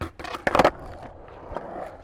Звуки скейтборда
Шум старта на скейтборде